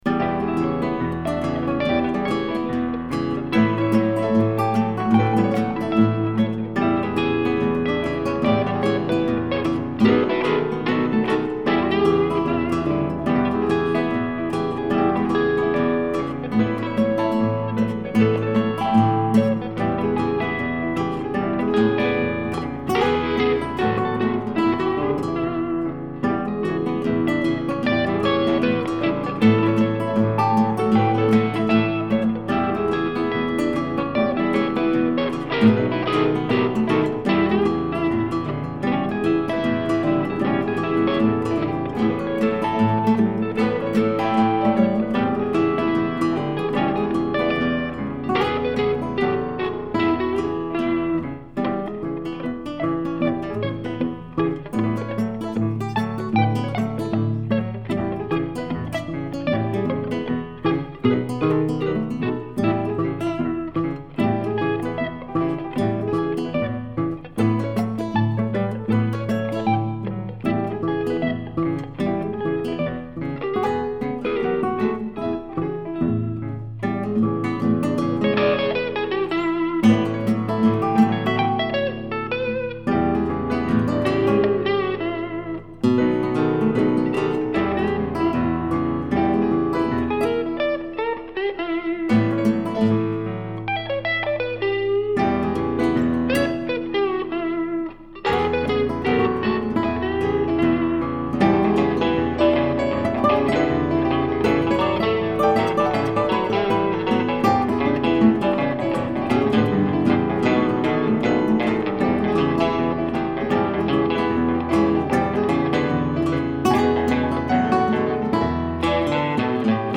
Classical Blues Cabaret